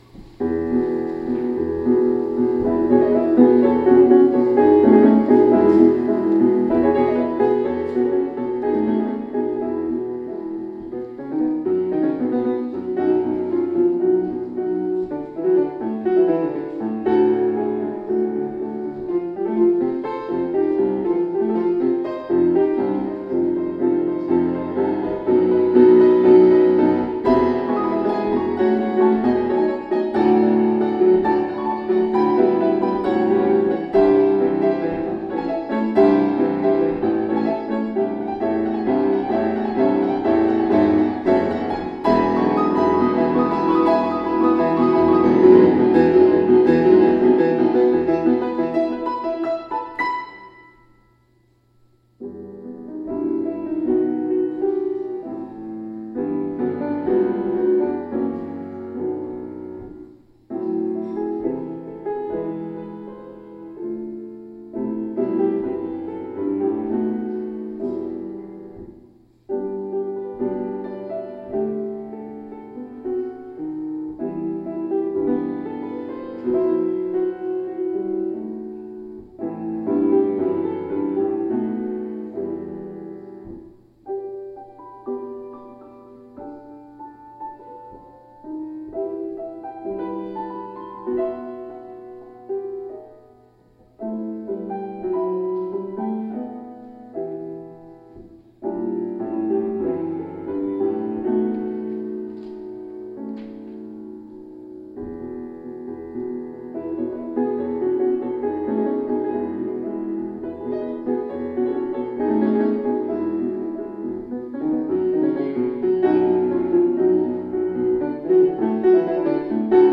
[Fuusm-l] POSTLUDE May 24, 2020